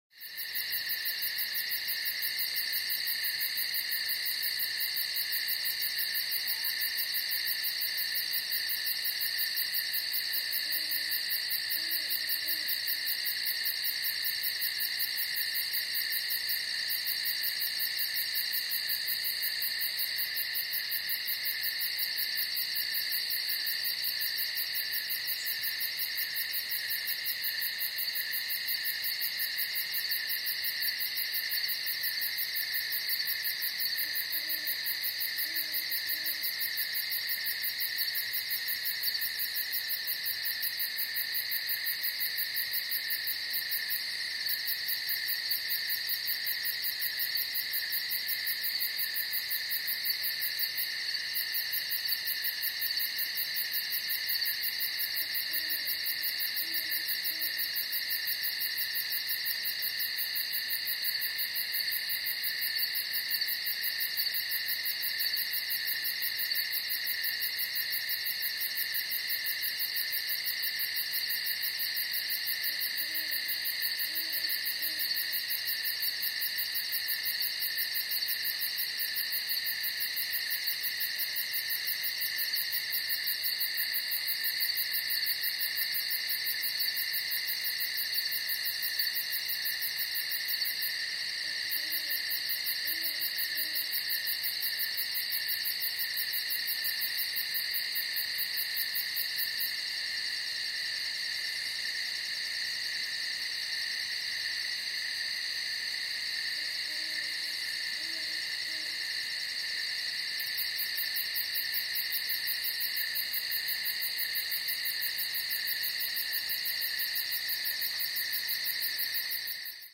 Звуки ночи
Лесная поляна в ночной тиши